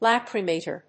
音節lach・ry・ma・tor 発音記号・読み方
/lˈækrɪmèɪṭɚ(米国英語)/